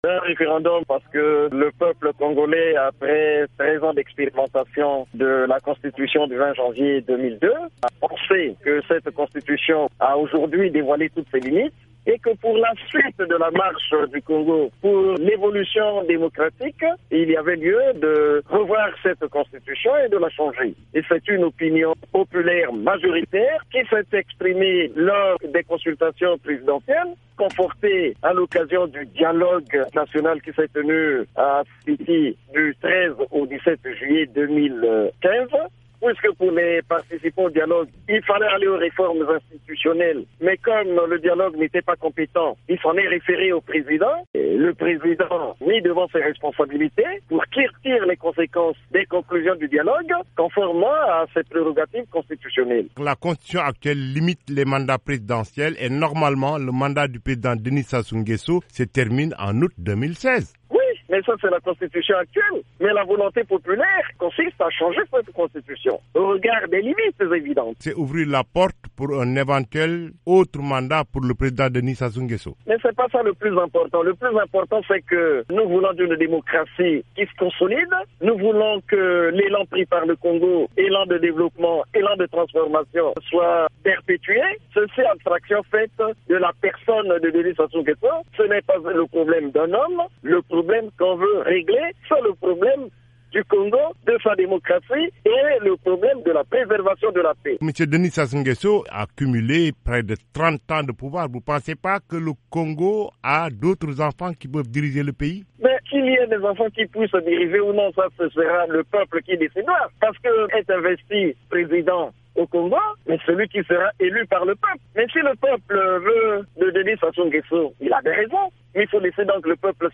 Pierre Ngolo, secrétaire général du Parti Congolais du Travail